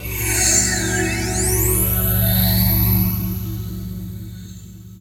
CHORD48 02-L.wav